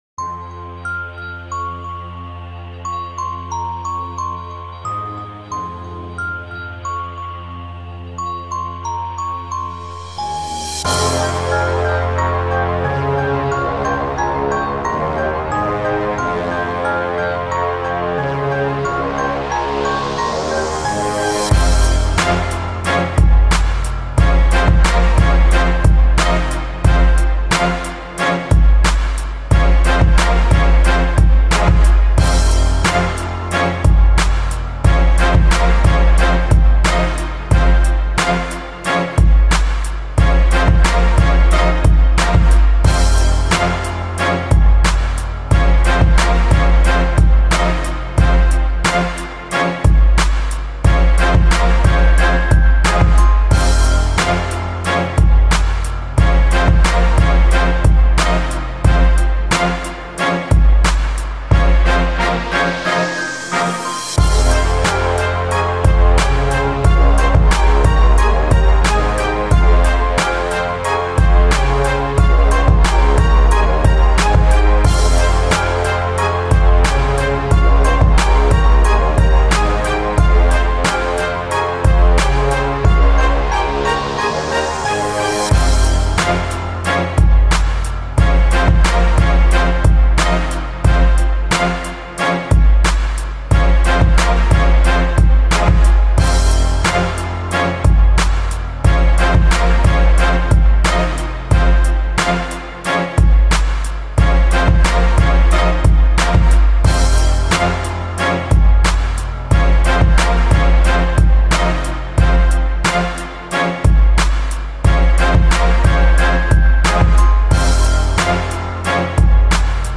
შესვლა  HipHop,Rap Instrumental